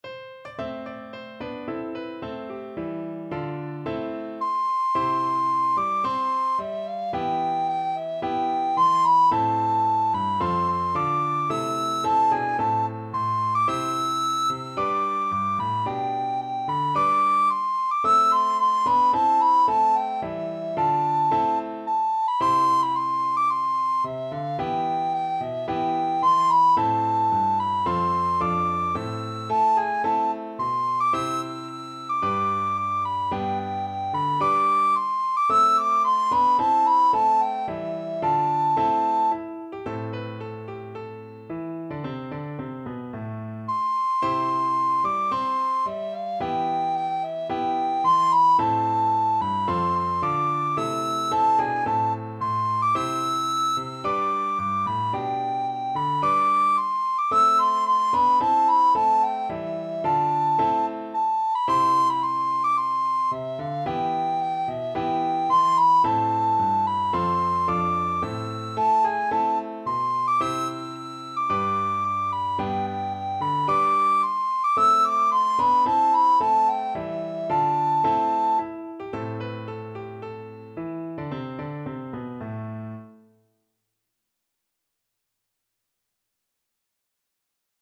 4/4 (View more 4/4 Music)
~ = 110 Allegro (View more music marked Allegro)